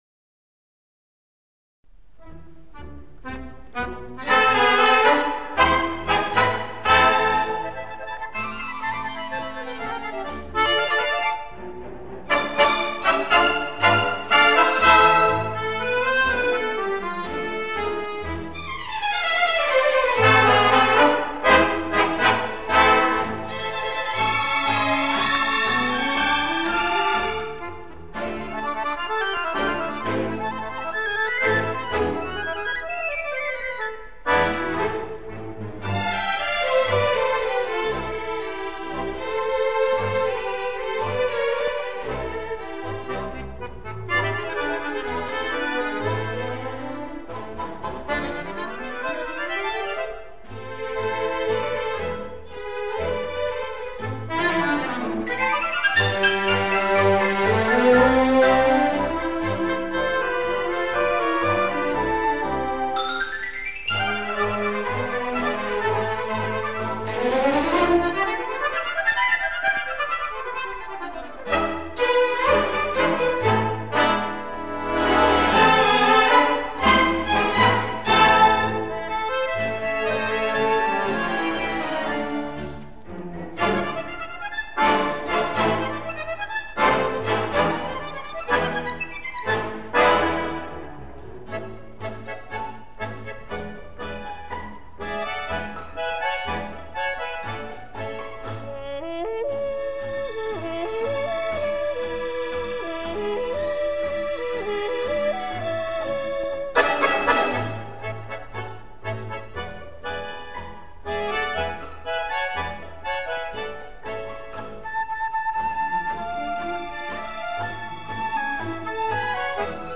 Genre:Latin
Style:Tango